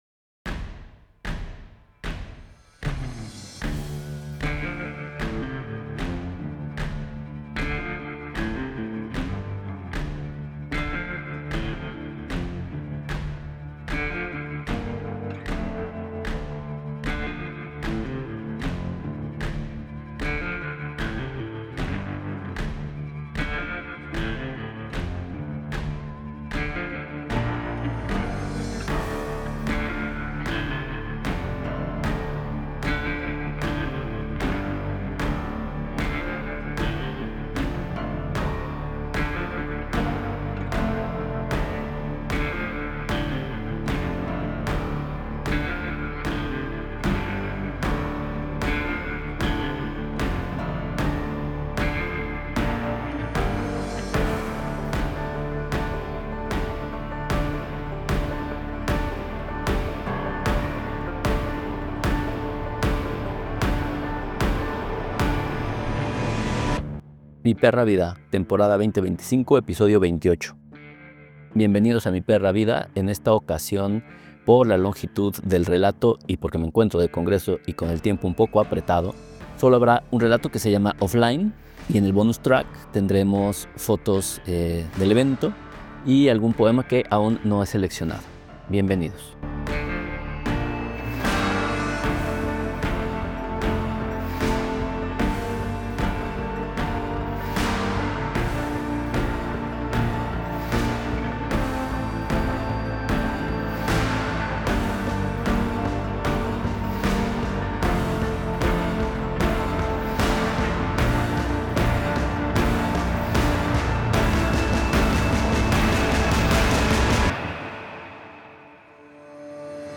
Relato – Offline | Bonus track